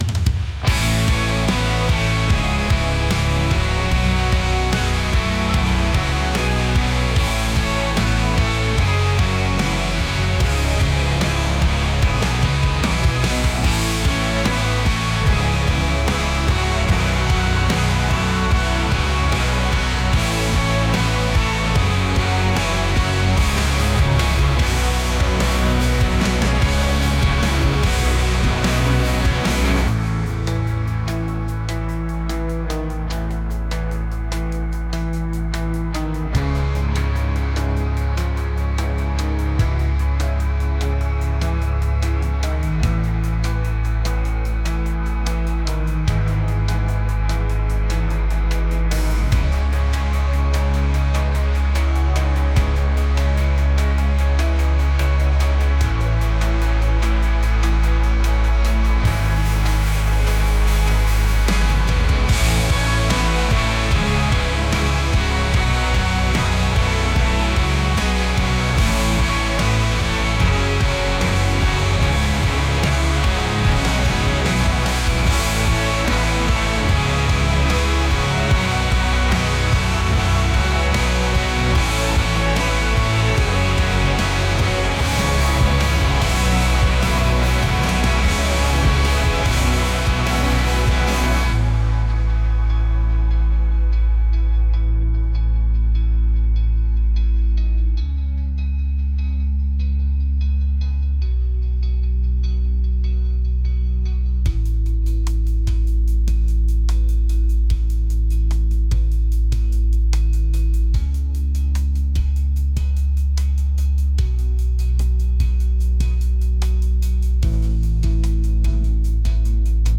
indie | rock | cinematic